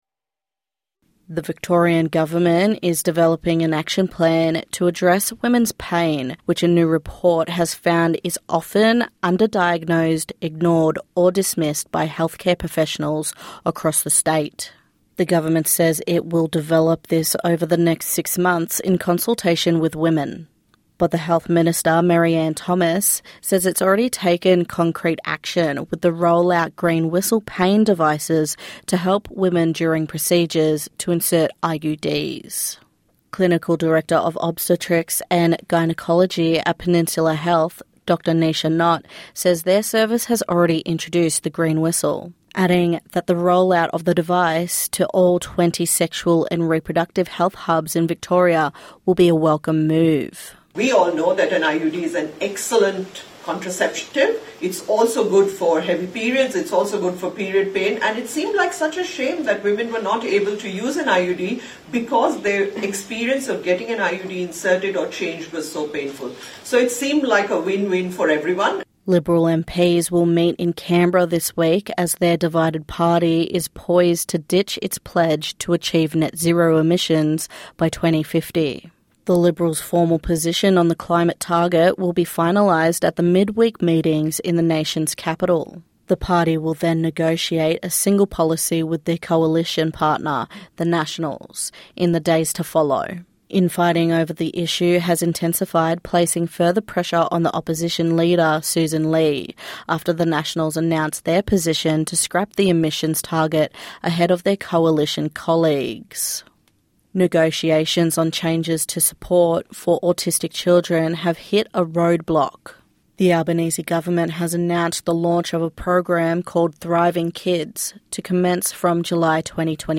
NITV Radio News - 10/11/2025